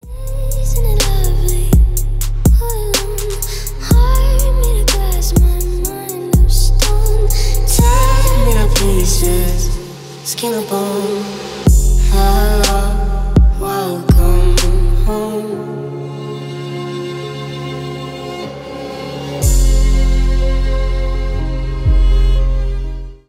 Ремикс
тихие